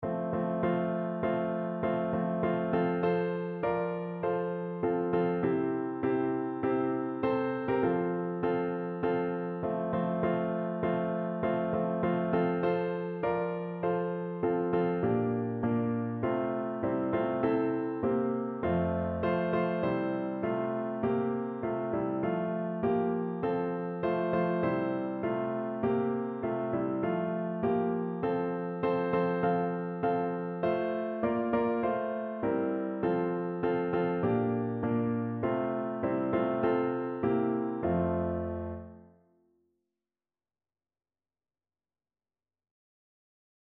Notensatz (4 Stimmen gemischt)